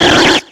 Cri de Kecleon dans Pokémon X et Y.